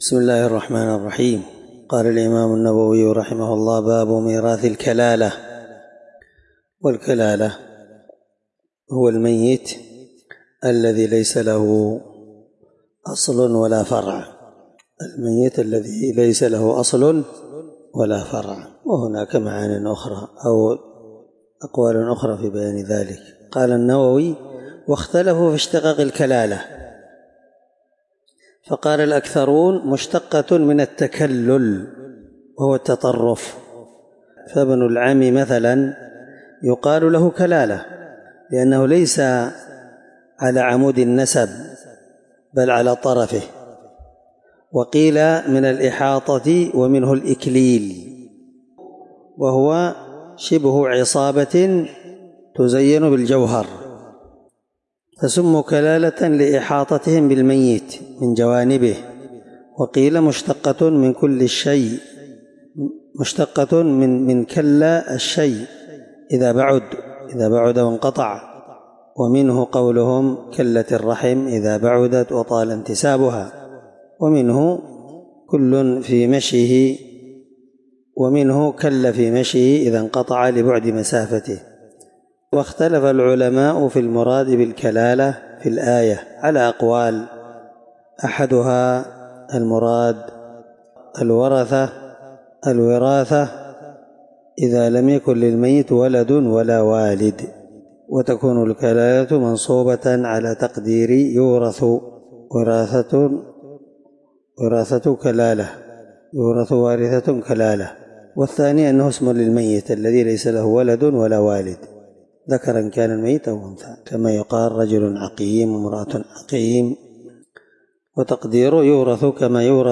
الدرس3من شرح كتاب الفرائض حديث رقم(1616) من صحيح مسلم